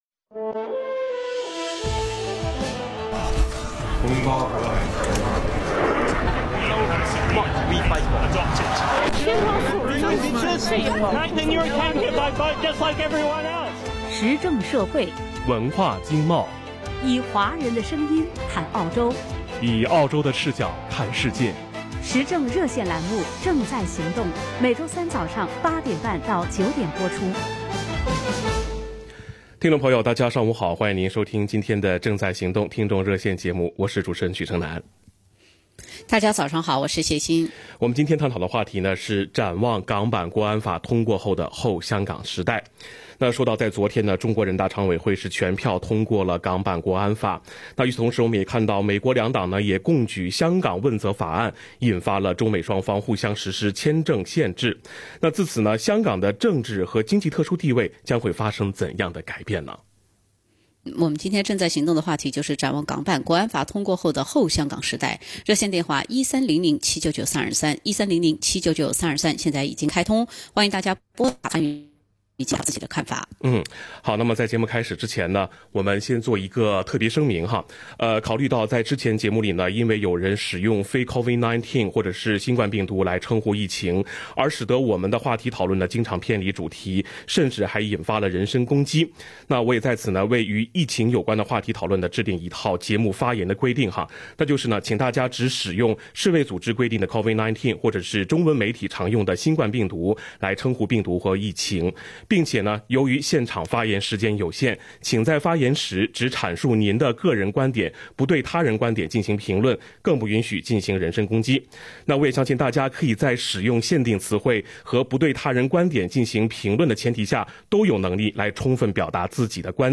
action_talkback_july_1_.mp3